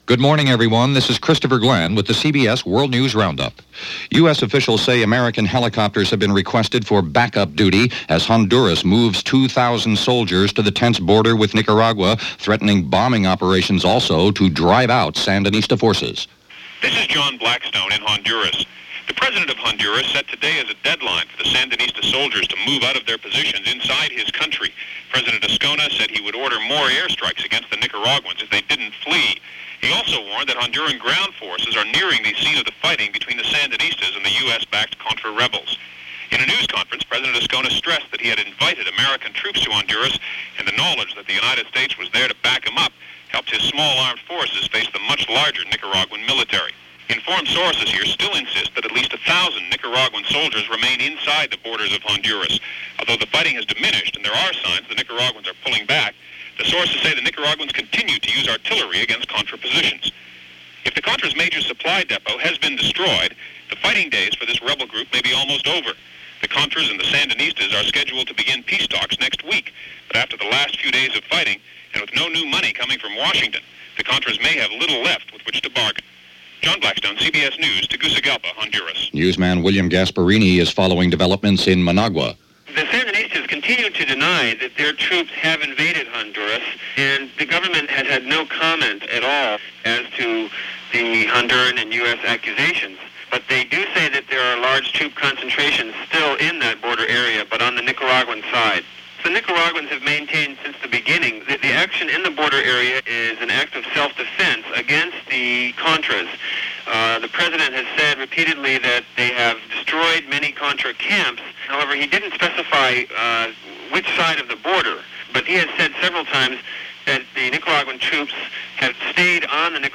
March 19, 1988 – CBS World News Roundup – Gordon Skene Sound Collection –